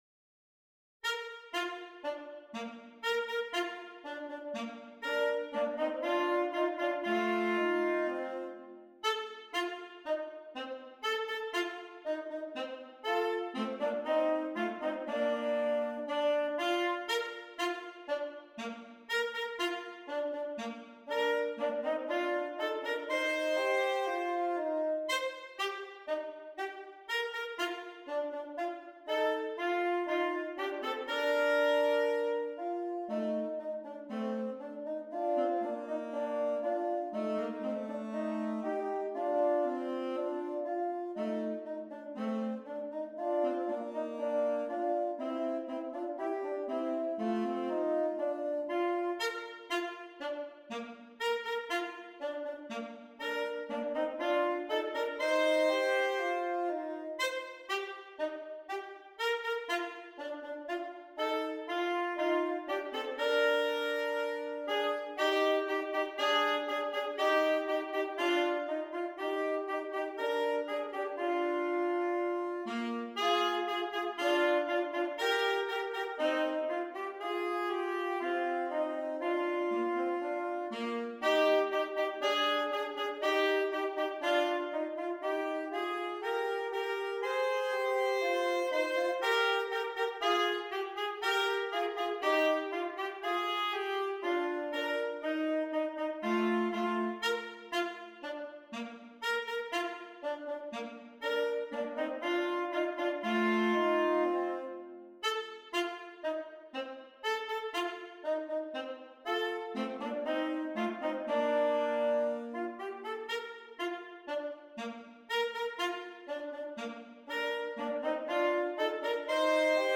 2 Alto Saxophones